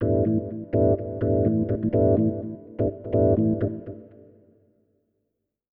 ORGAN004_VOCAL_125_A_SC3(L).wav